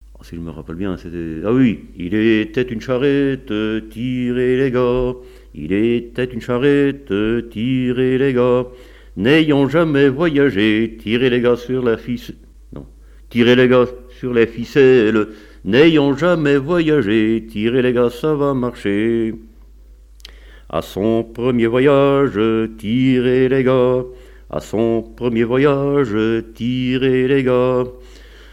Genre laisse
Chansons et témoignages sur le chanson et la musique
Pièce musicale inédite